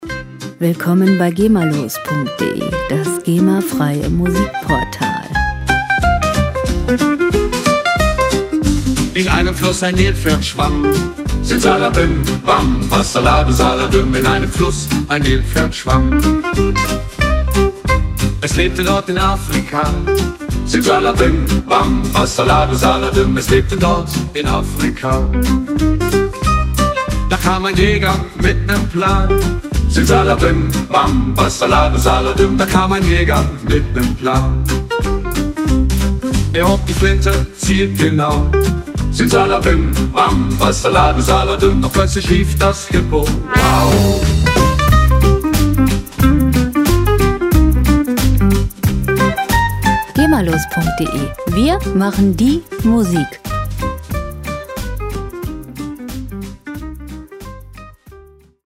Gema-freie Kinderlieder
Musikstil: Gypsy
Tempo: 91 bpm
Tonart: C-Moll
Charakter: witzig, lustig